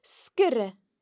The slender sgr can be heard in sgrìobh (wrote):